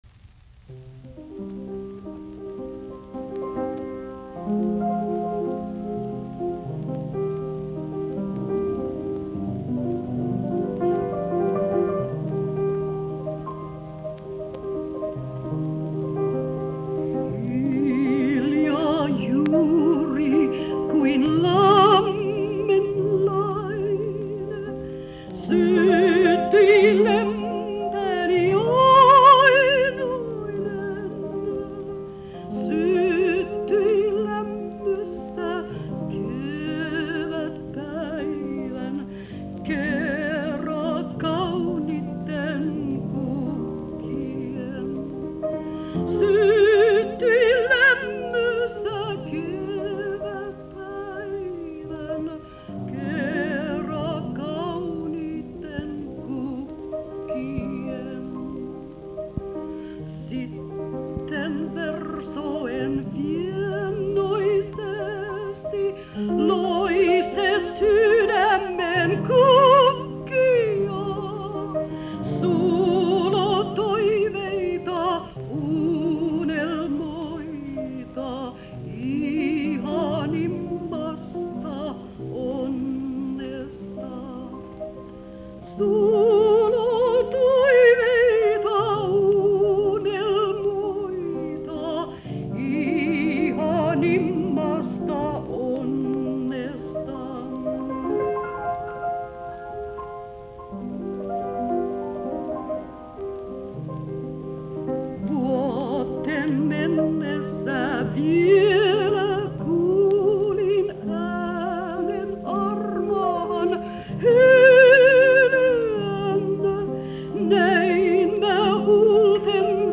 musiikkiäänite